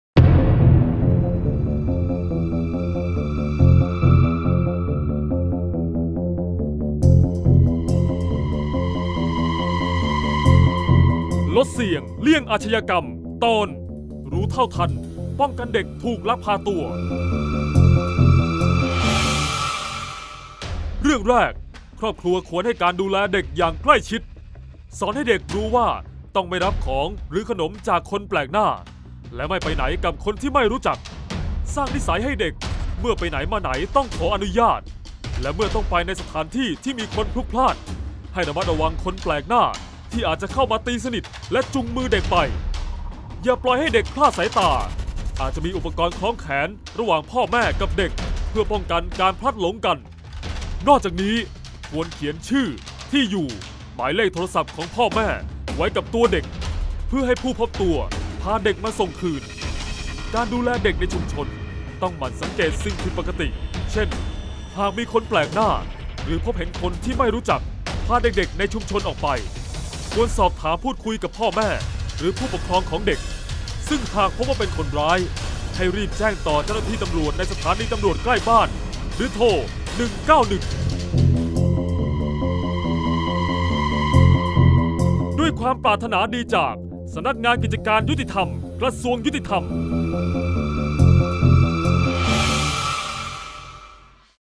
เสียงบรรยาย ลดเสี่ยงเลี่ยงอาชญากรรม 17-ป้องกันเด็กถูกลักพาตัว